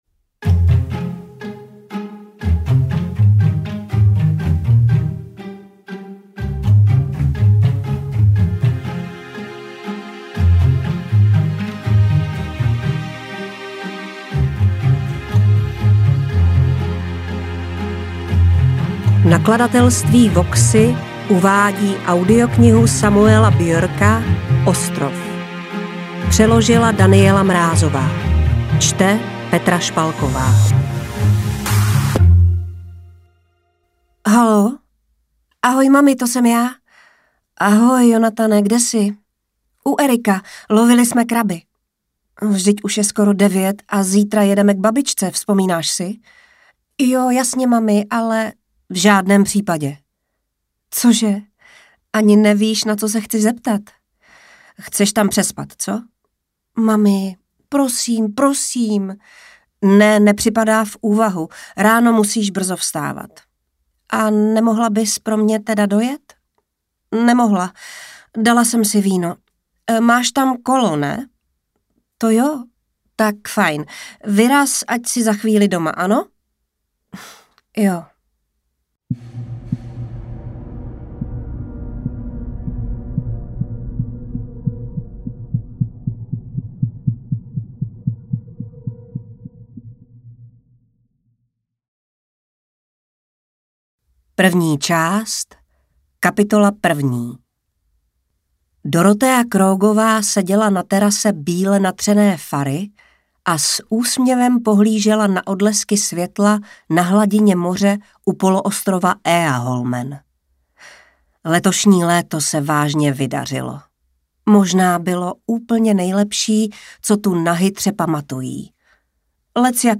Interpret:  Petra Špalková
AudioKniha ke stažení, 93 x mp3, délka 13 hod. 47 min., velikost 750,3 MB, česky